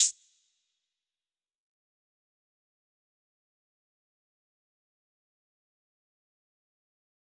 Closed Hats
DMV3_Hi Hat 11.wav